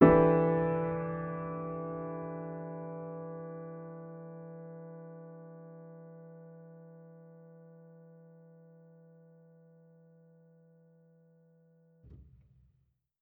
Index of /musicradar/jazz-keys-samples/Chord Hits/Acoustic Piano 2
JK_AcPiano2_Chord-Em11.wav